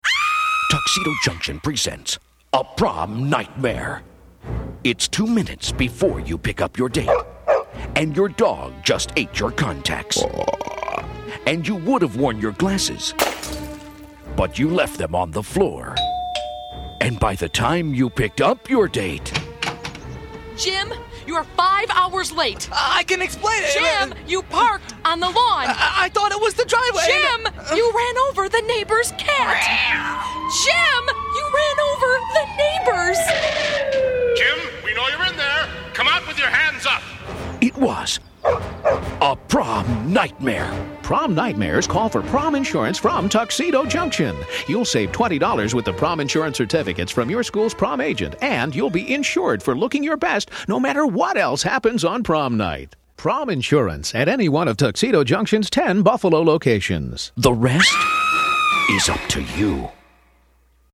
Through a series of four 60-second radio spots, we created a memorable campaign geared towards teens. Each spot featured a humorous "prom nightmare," encouraging kids to purchase prom insurance from Tuxedo Junction.